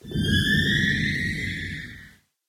should be correct audio levels.
cave10.ogg